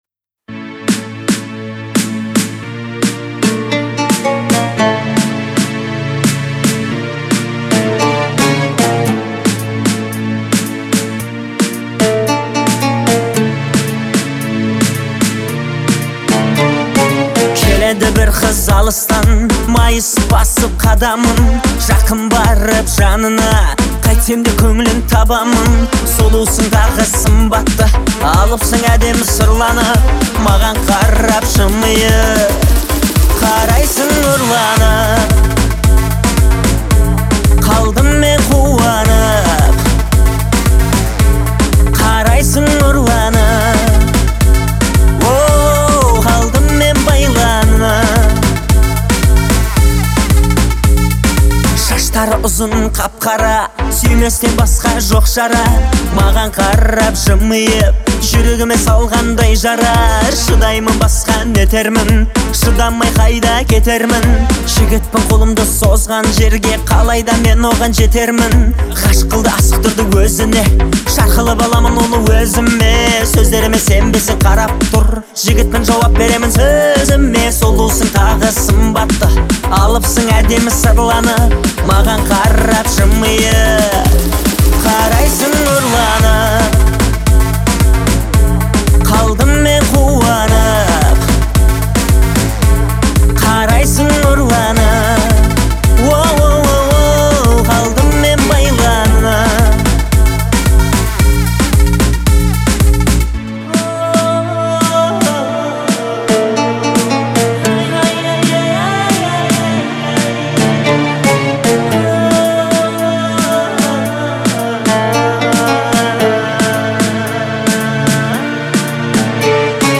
это трогательное произведение в жанре казахского поп-фолка.